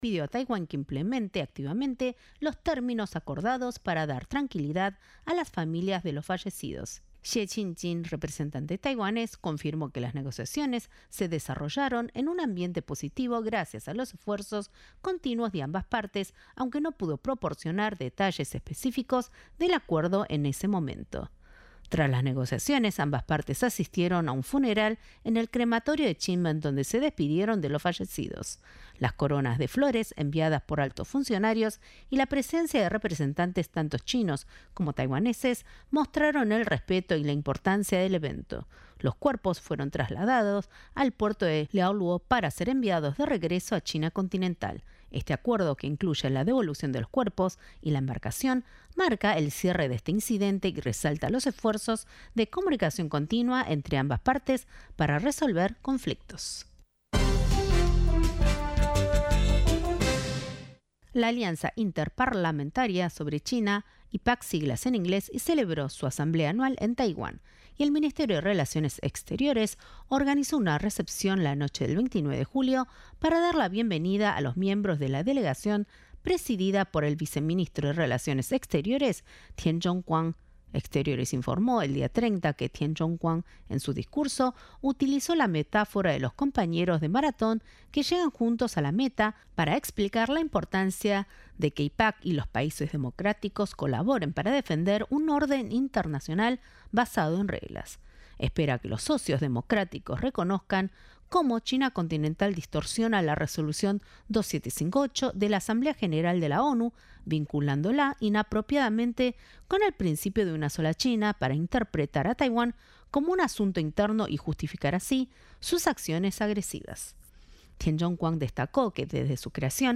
Propietario de una casa de venta de armas está investigado por tráfico internacional, confirmó este martes en rueda de prensa realizada en la Residencia Presidencial de Mburuvichá Róga, el director de Dimabel, general Melanio Servín.